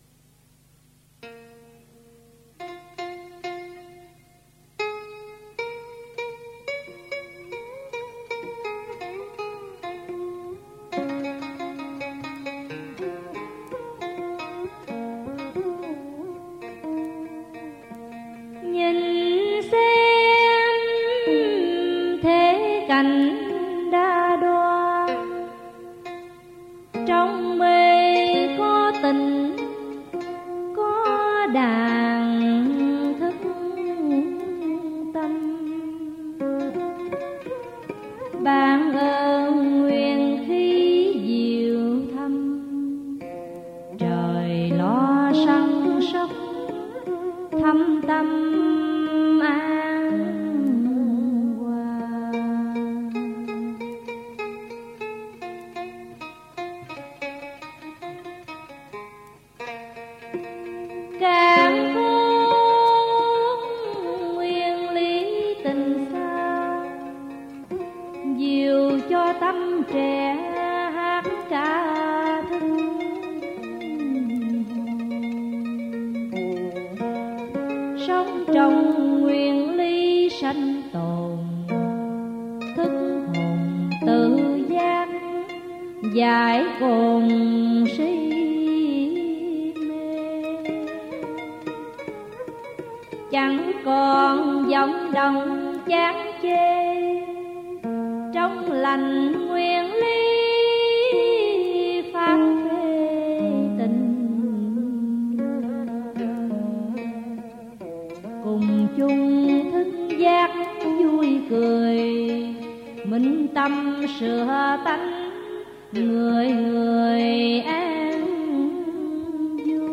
Dân Ca & Cải Lương
theo điệu nói thơ Lục Văn Tiên